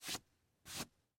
Окунитесь в мир необычных звуков: здесь собраны записи шорохов, оседания и движения пыли.
Звук смахивания пыли с одежды